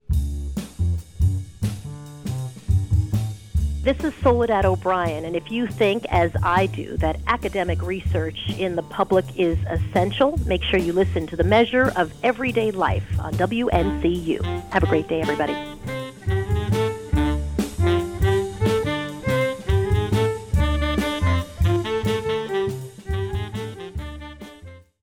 She also offered a few words of support.
soledad-obrien-promo1.wav